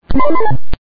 Identifier Sound Effects
The sound bytes heard on this page have quirks and are low quality.